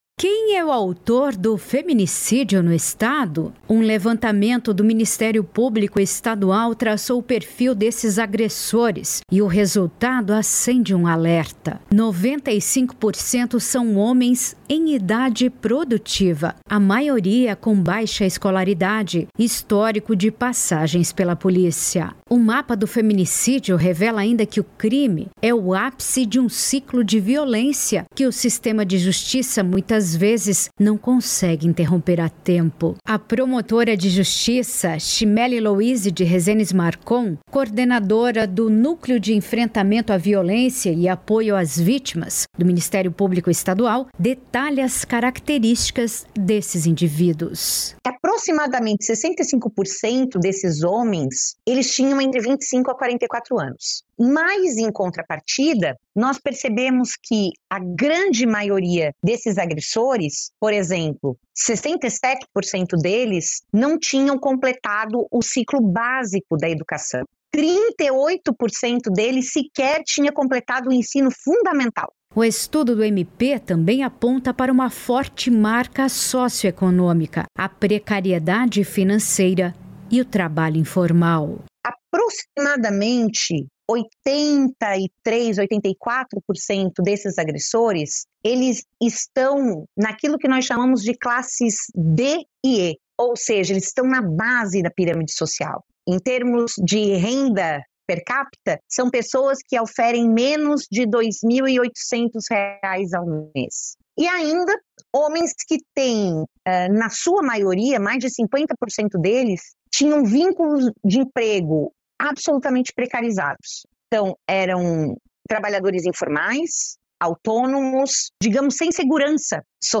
Entrevistada: